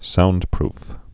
(soundprf)